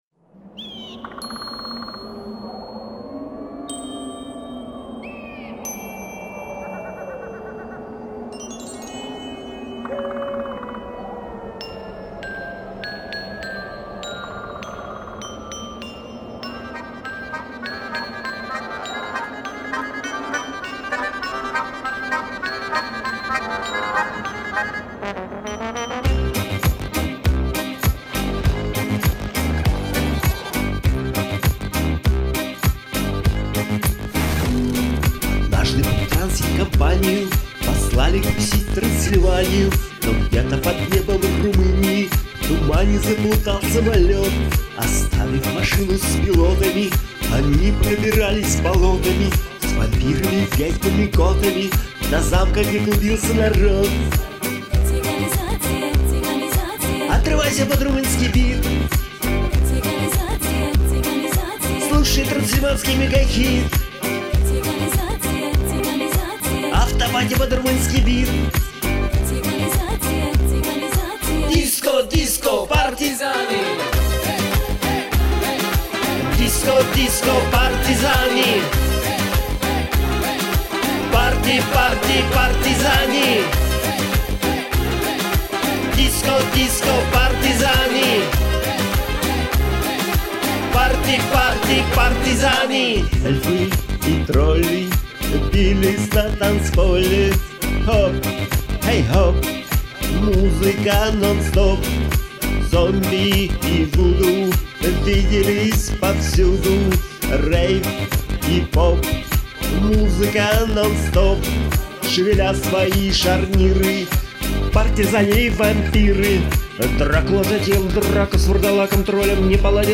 Молодцы- красиво и ритмично!! bravo_bigbuket_serdechkibuket